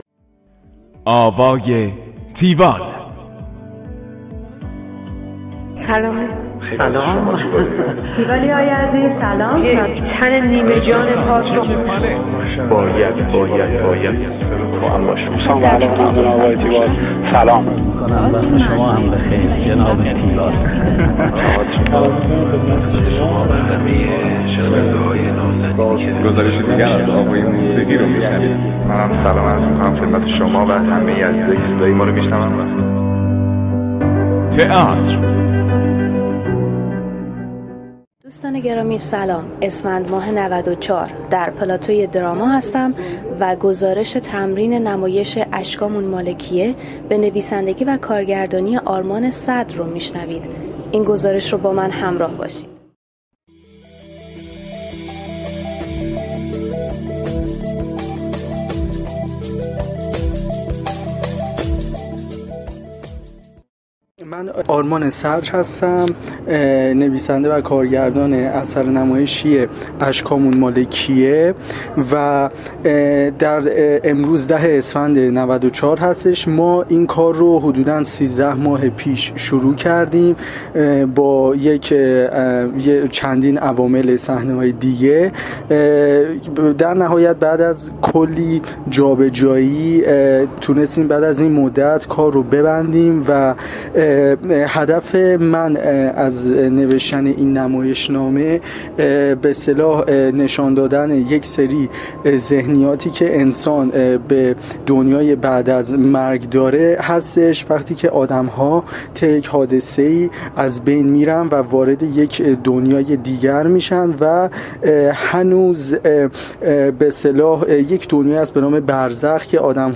گزارش آوای تیوال از نمایش اشکامون مال کیه
گفتگو با